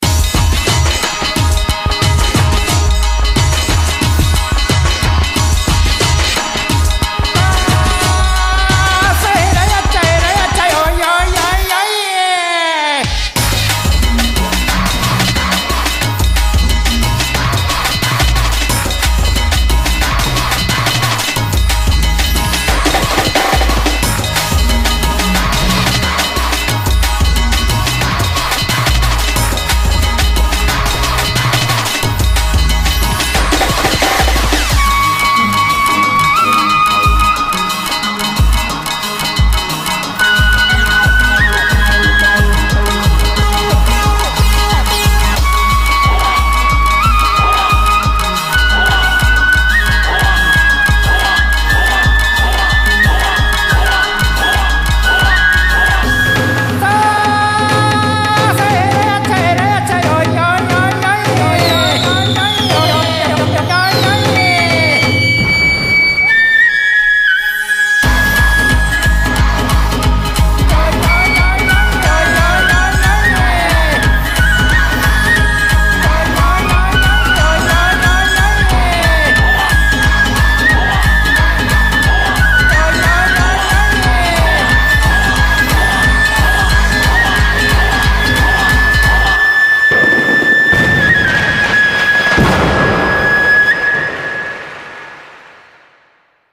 BPM180
Audio QualityMusic Cut